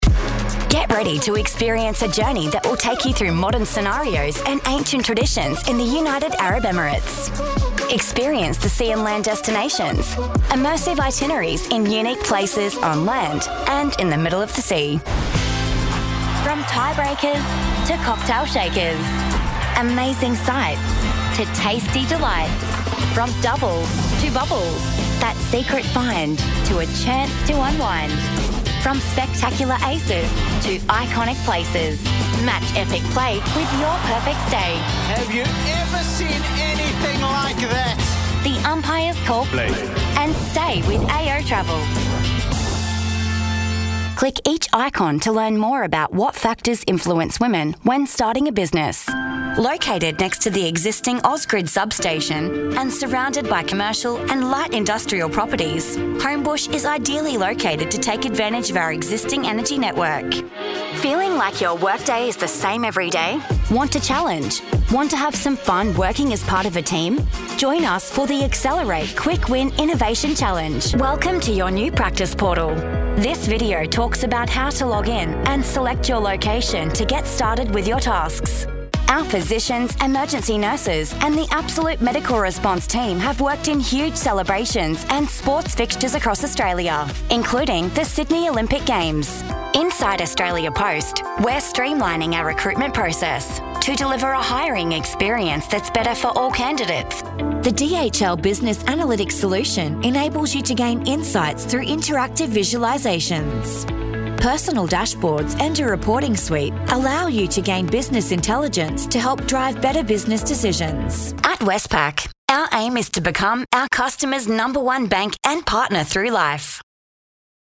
Hire professional & affordable English (Australian) voice actors with FREE auditions. 24/7 support.